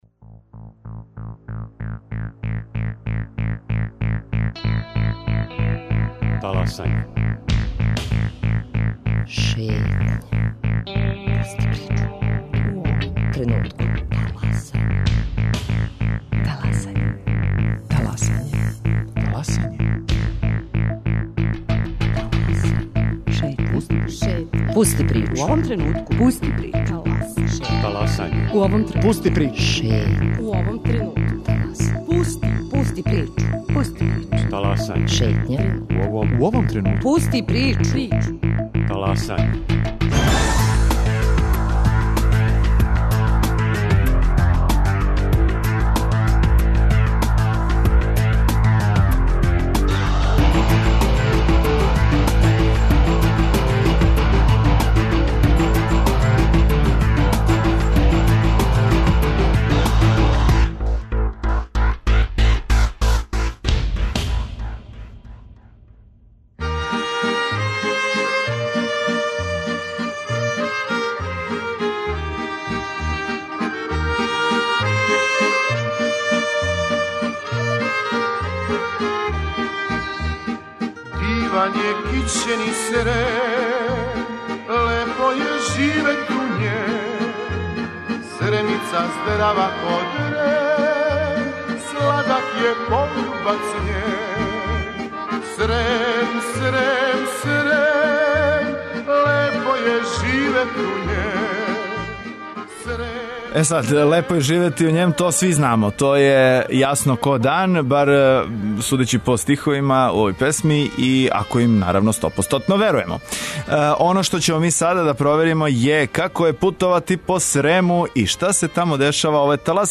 Овога петка први сат 'Таласања' реализујемо из Сремске Митровице, којој је у посети велика екипа Радио Београда 1.